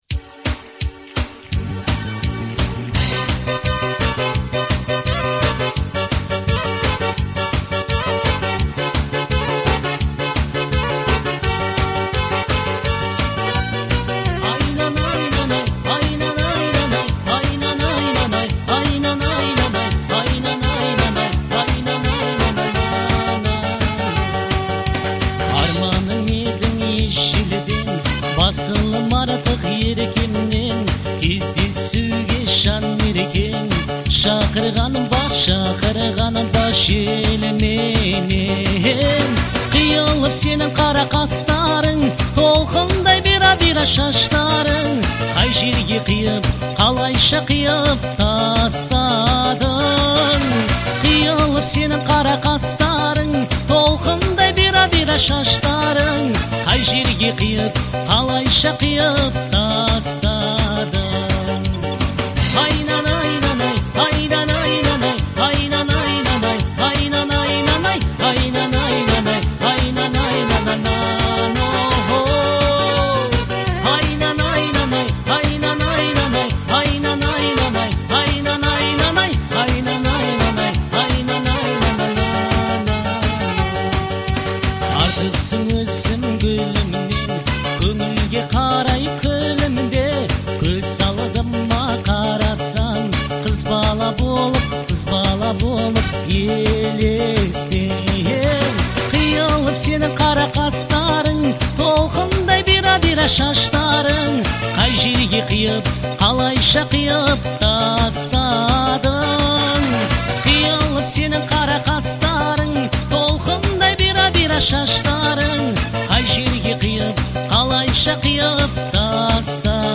это романтическая казахская песня в жанре поп-фолк